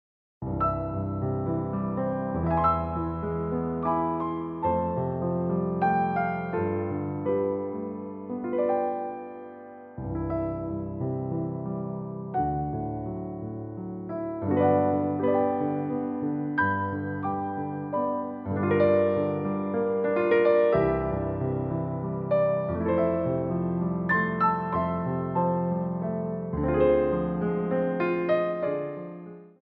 Ports de Bras / Revérance
3/4 (8x8)